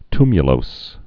(tmyə-lōs, ty-) also tu·mu·lous (-ləs)